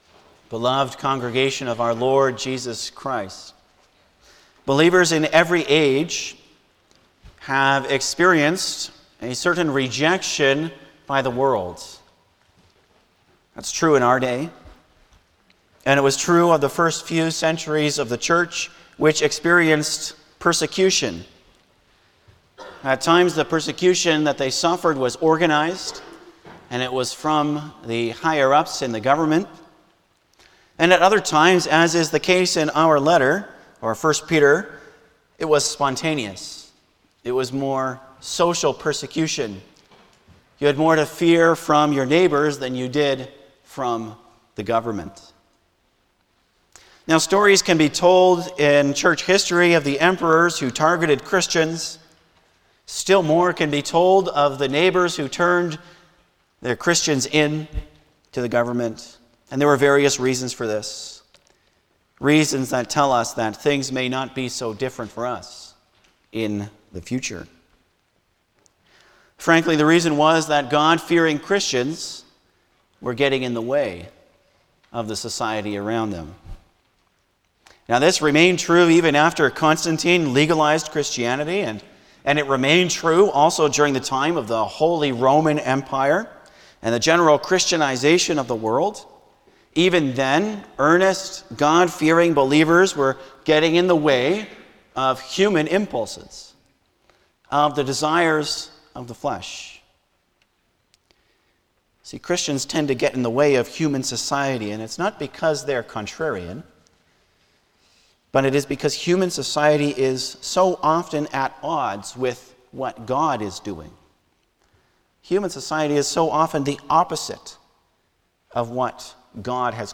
Passage: 1 Peter 1: 1,2 Service Type: Sunday morning
09-Sermon.mp3